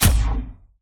etfx_explosion_magic2.wav